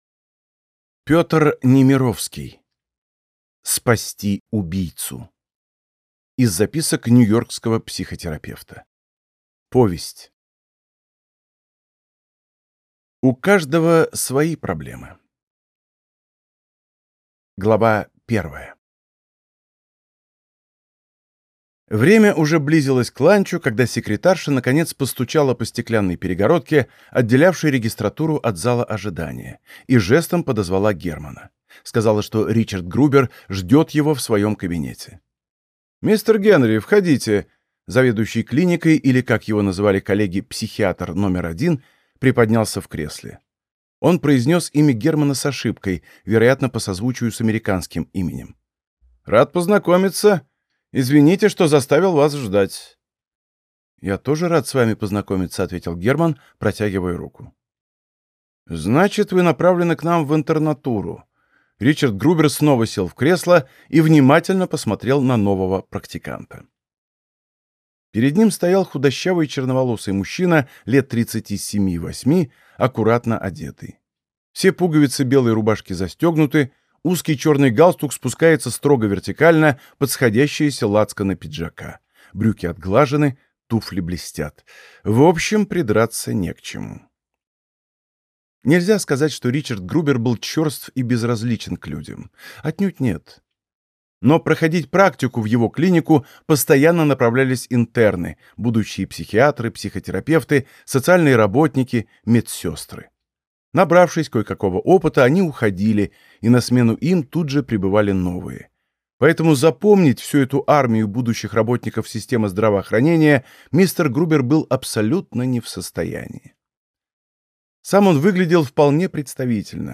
Аудиокнига Спасти убийцу | Библиотека аудиокниг